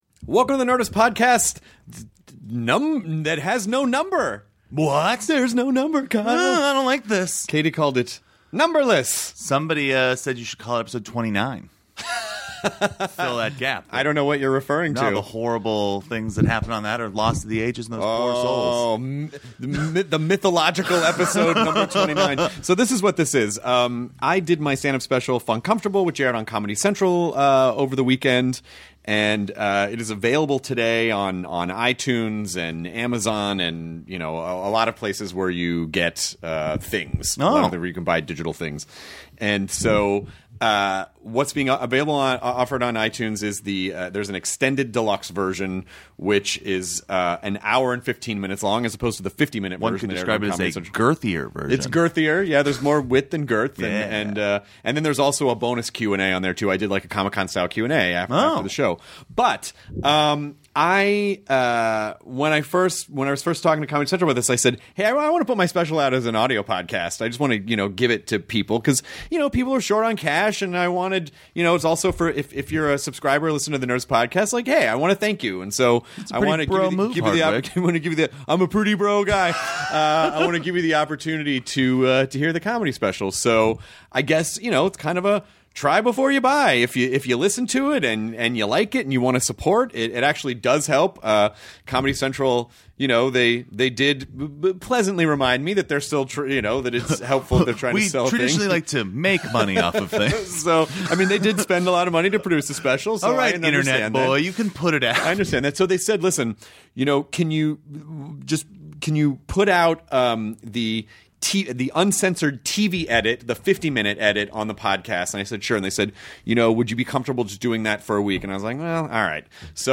As a special thanks to the podcast listeners, Chris is releasing his newest Comedy Central special Funcomfortable for a limited time!